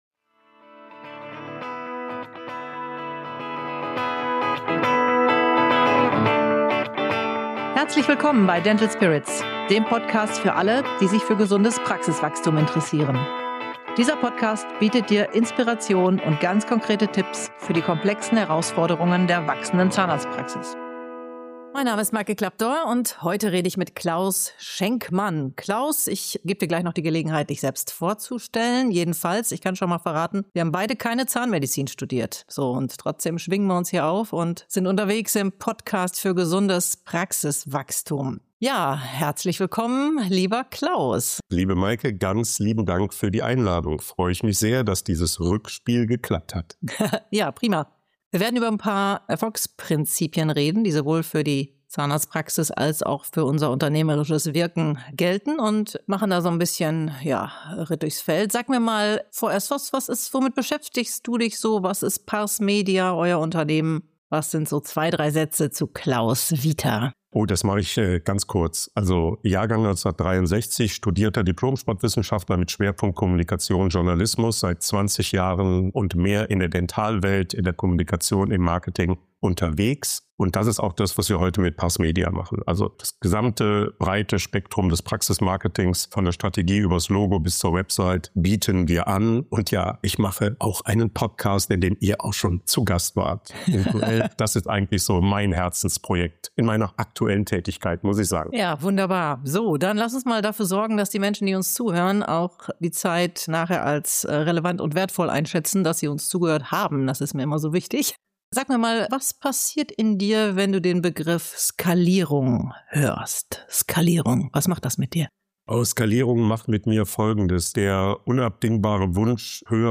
Ein Gespräch für alle, die Praxisentwicklung auch als persönliche Entwicklungsreise begreifen.